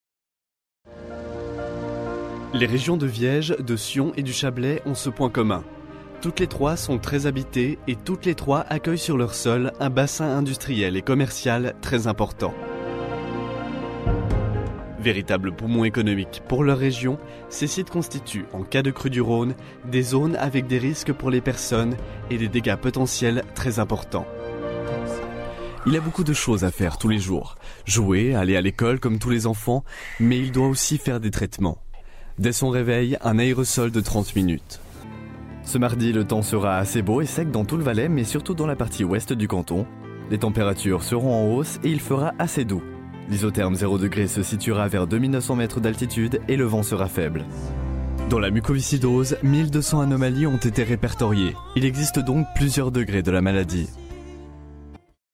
法语样音试听下载
法语配音员（男1） 法语配音员（男2）